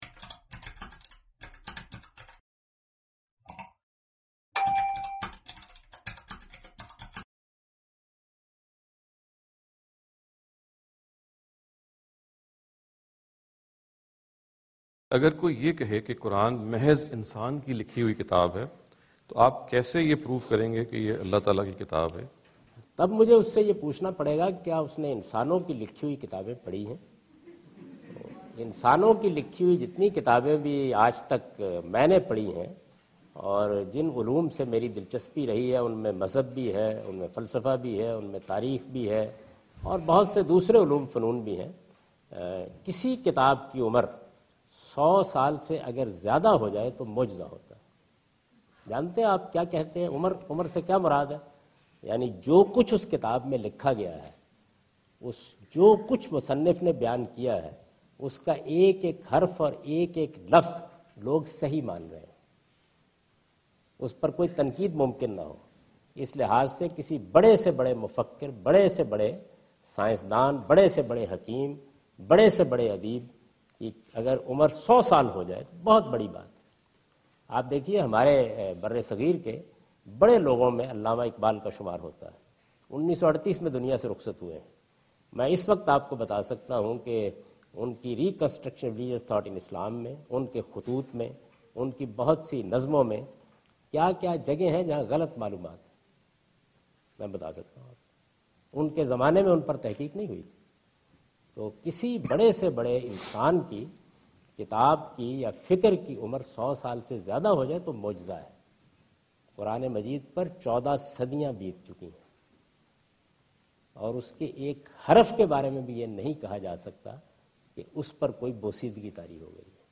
Javed Ahmad Ghamidi answer the question about "Proving Quran a Divine Book" during his visit to Georgetown (Washington, D.C. USA) May 2015.
جاوید احمد غامدی اپنے دورہ امریکہ کے دوران جارج ٹاون میں "قرآن کو الہامی کتاب کیسے ثابت کیا جائے؟" سے متعلق ایک سوال کا جواب دے رہے ہیں۔